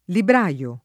vai all'elenco alfabetico delle voci ingrandisci il carattere 100% rimpicciolisci il carattere stampa invia tramite posta elettronica codividi su Facebook libraio [ libr #L o ] s. m.; pl. librai — dim. libraino [ libra & no ]